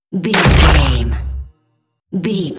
Dramatic deep hit bloody
Sound Effects
heavy
intense
dark
aggressive
hits